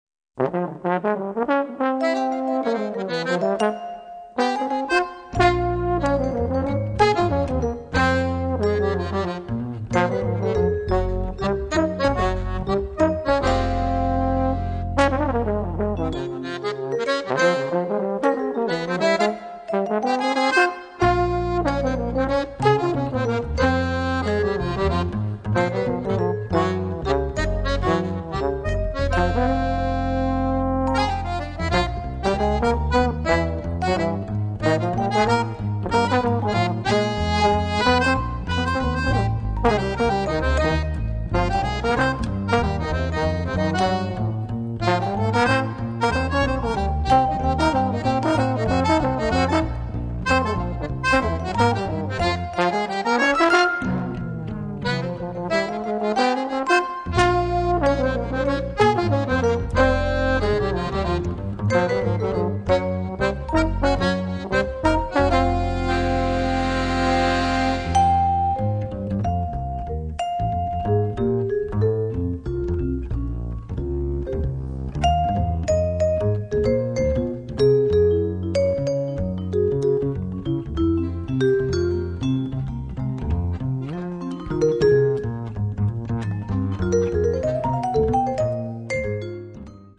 Auditorium di S. Cecilia - Perugia